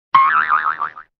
bounce2.wav